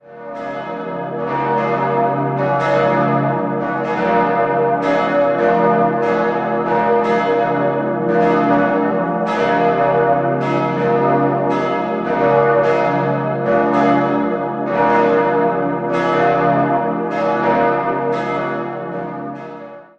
3-stimmiges Te-Deum-Geläut: h°-d'-e' Alle drei Glocken des Hauptgeläuts wurden im Jahr 1814 von Friedrich August Otto in Dresden gegossen.